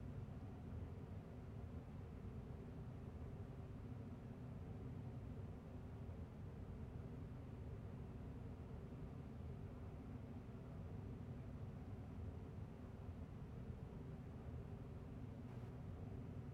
sfx-jfe-amb-loop-2.ogg